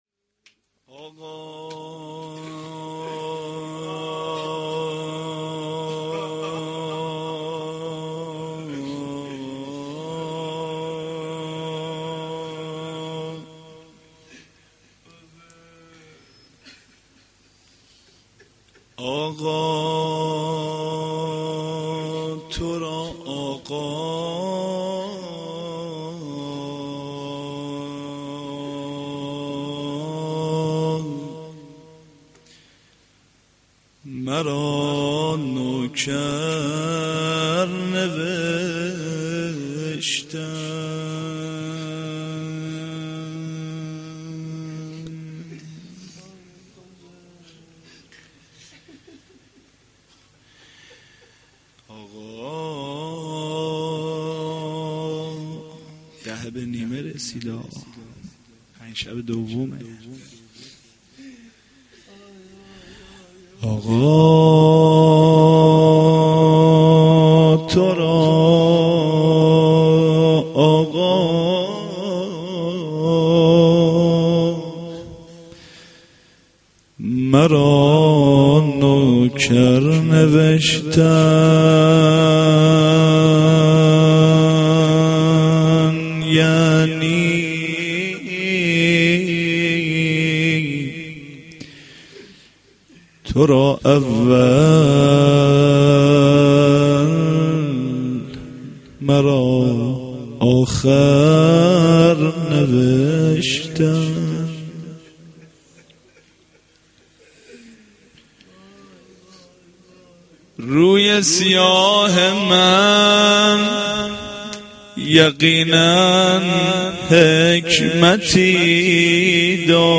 sh-6-moharram-92-roze.mp3